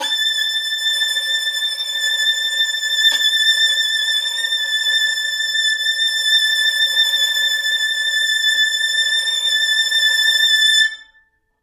interactive-fretboard / samples / violin / A6.wav